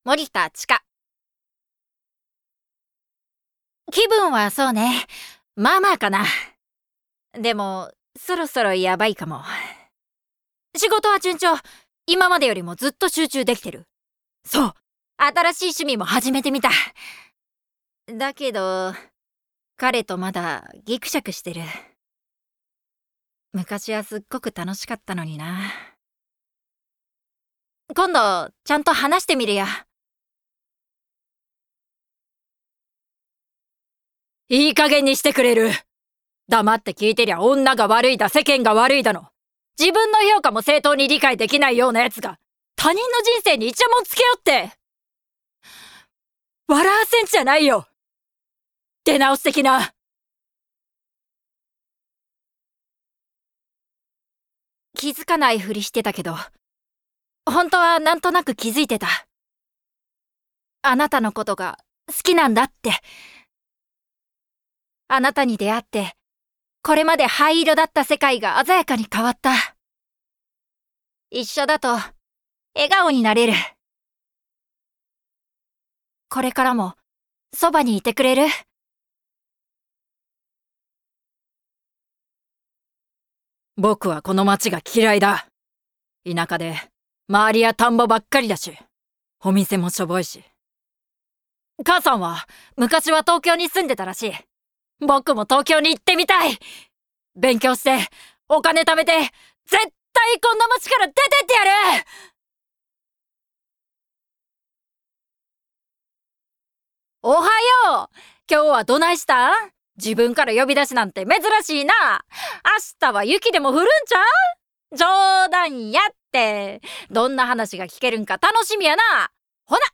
方言: 大阪弁
VOICE SAMPLE